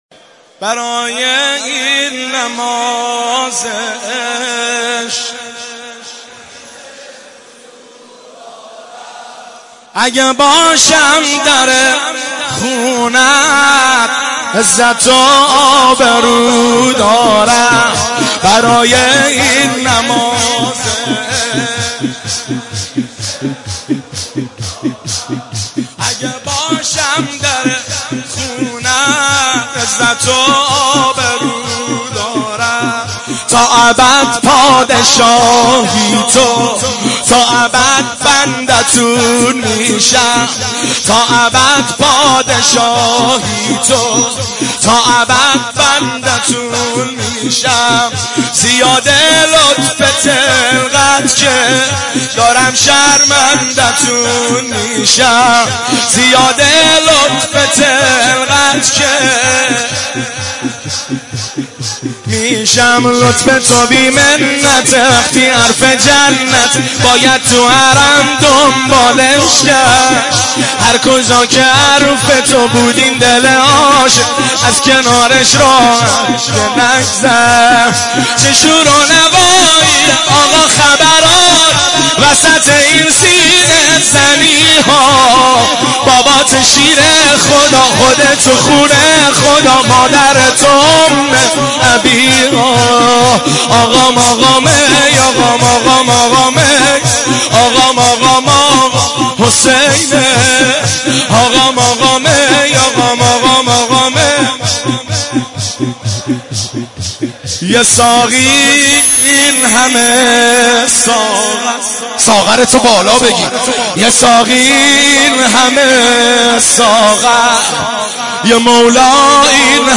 شب عاشورا
مداحی
نوحه